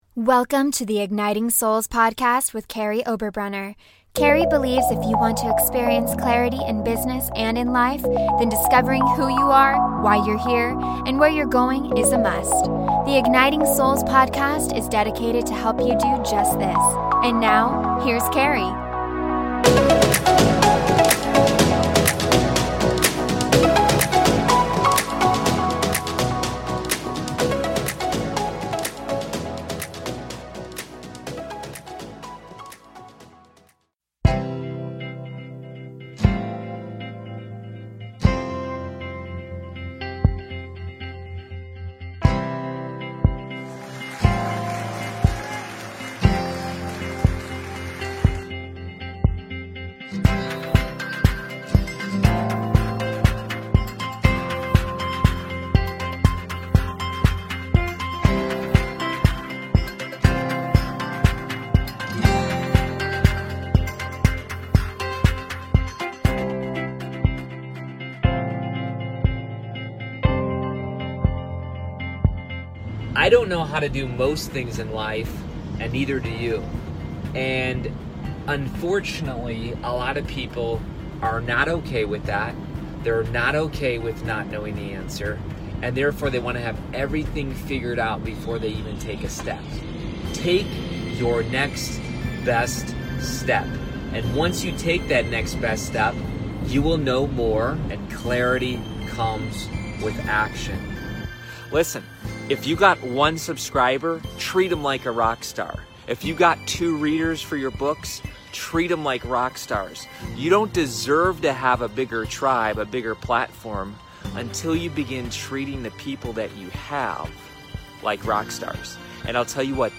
Check out my recent interview for Insider Writer's Bubble about pain, reasons to write a book, my story, the importance of clarity and the benefits of writing your book in community.